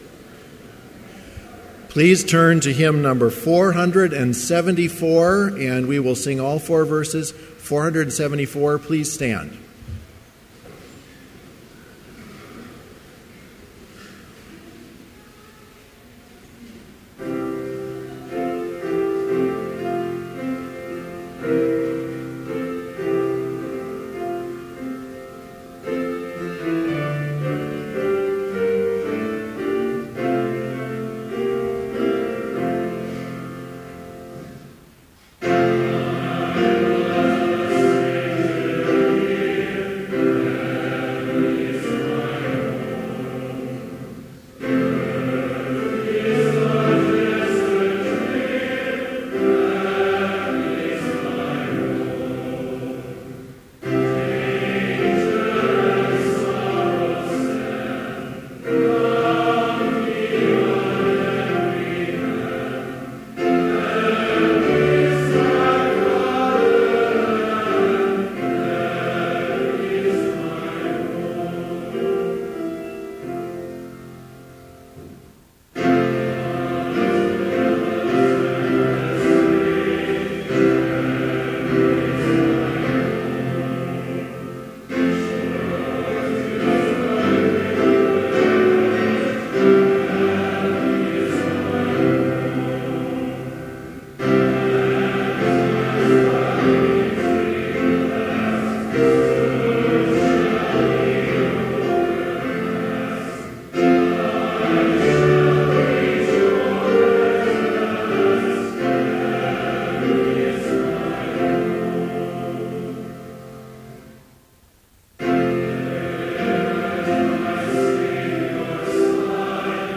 Chapel in Trinity Chapel, Bethany Lutheran College, on November 11, 2014, (audio available) with None Specified preaching.
Complete service audio for Chapel - November 11, 2014